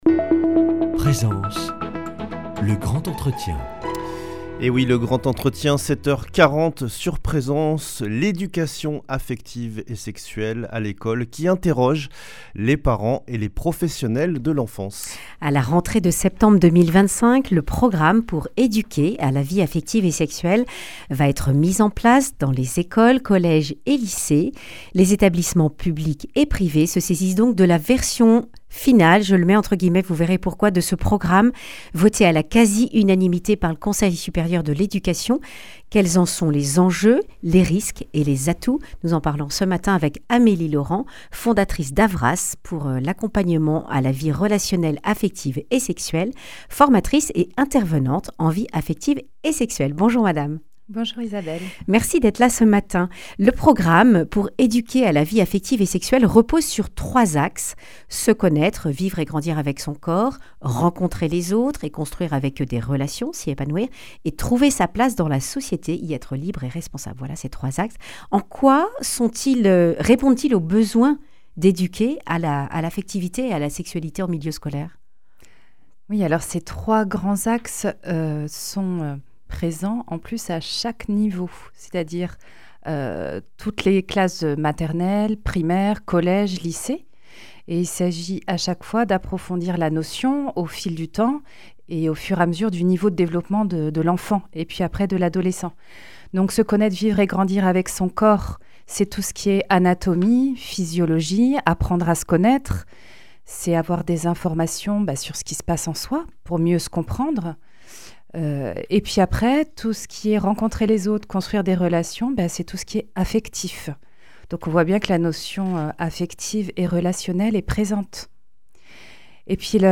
Accueil \ Emissions \ Information \ Régionale \ Le grand entretien \ Education relationnelle, affective et sexuelle à l’école : quel contenu (...)